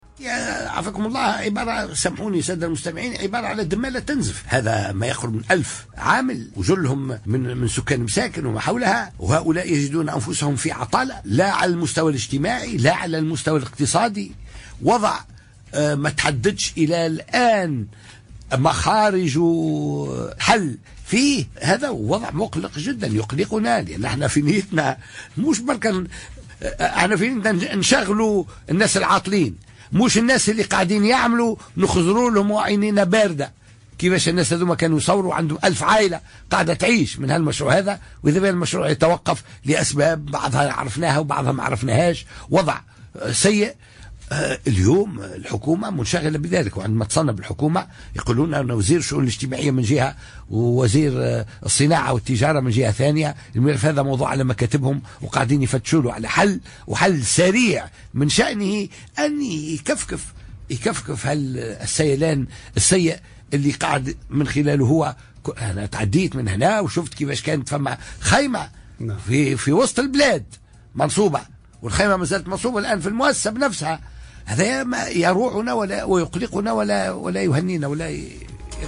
قال نائب رئيس مجلس الشعب و القيادي بحركة النهضة عبد الفتاح مورو خلال حضوره امس في حوار حصري لراديو ار ام ان وضع شركة ستيب مقلق كما عبر عن تضامنه مع العمال